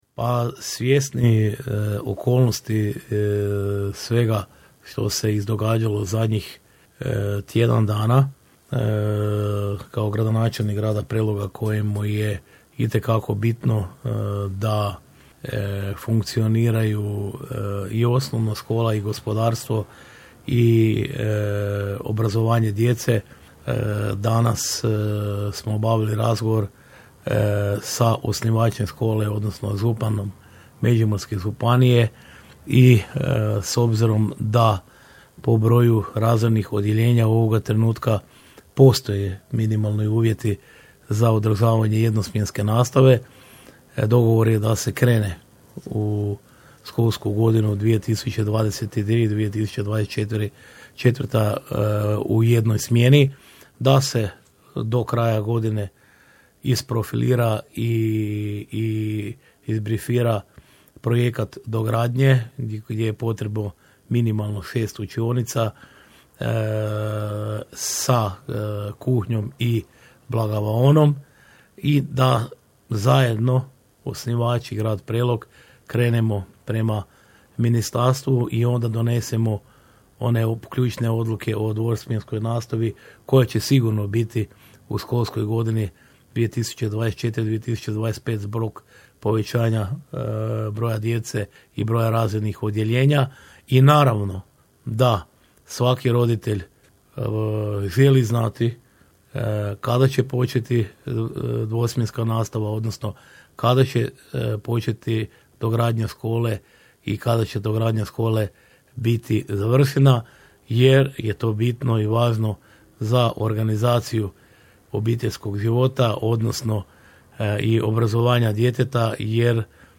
Iako se za nadolazeću školsku godinu najavljivala dvosmjenska nastava u OŠ Prelog, ovdašnji osnovnoškolci ipak će nastavu polaziti u jednoj smjeni, rekao je danas u našem programu gradonačelnik Ljubomir Kolarek.
Donosimo cijelu izjavu: